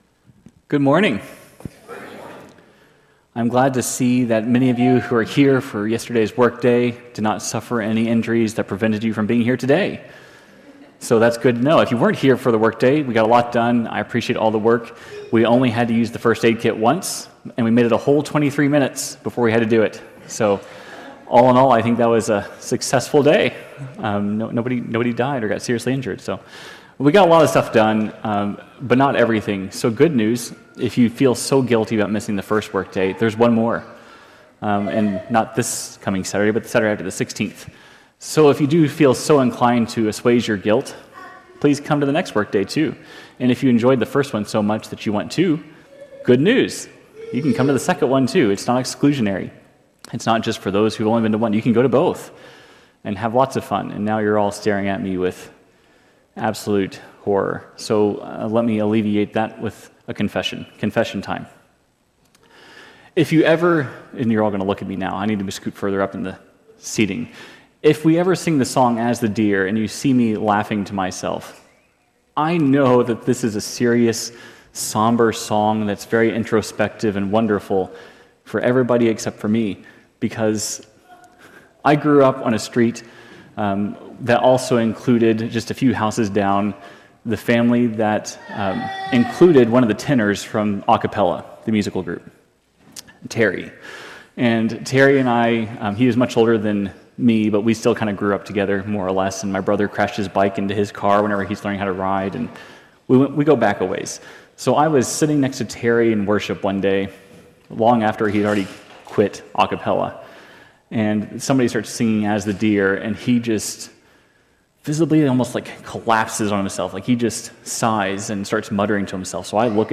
The psalm confronts the reality of death and the emptiness of placing hope in riches or status. It challenges us to confront life’s ultimate uncertainties and false securities. This sermon invites honest reflection on what truly lasts and calls us to seek deeper, lasting meaning in God.